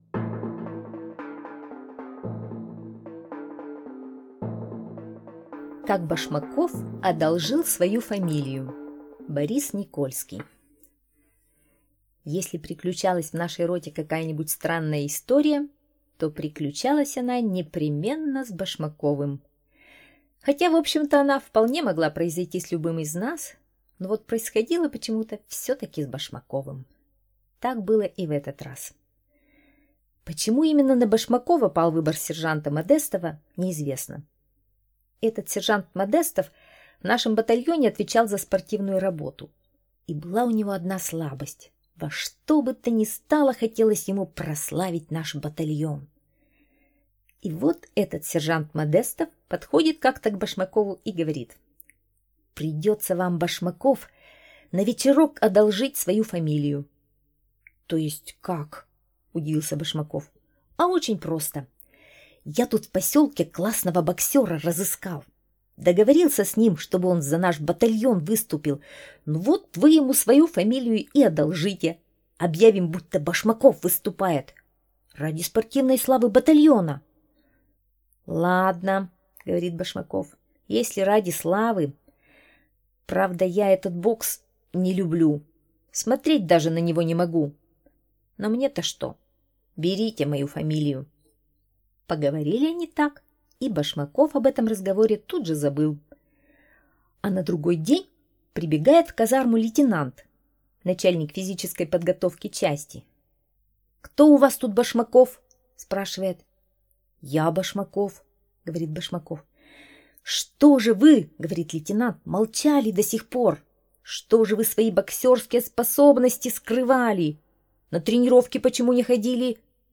Аудиорассказ «Как Башмаков одолжил свою фамилию»